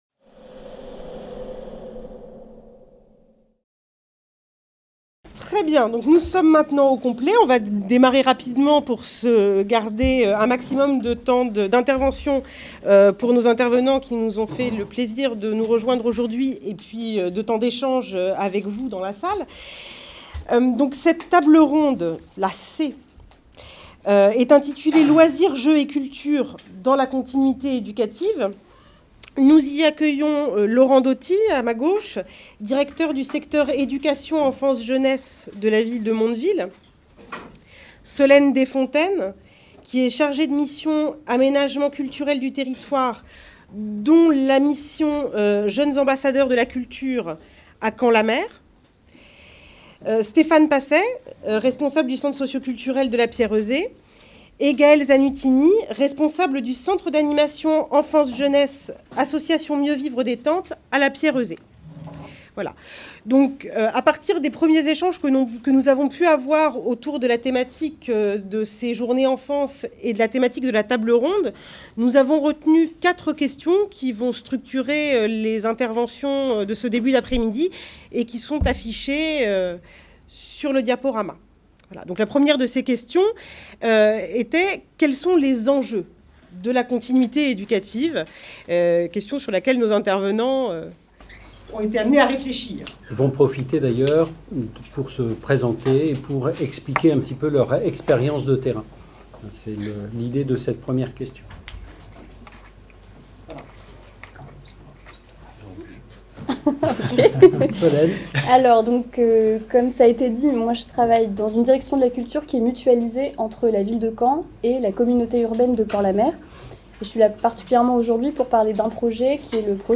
CIRNEF18 | 04 - Table ronde C : Loisirs, jeu et culture dans la continuité éducative | Canal U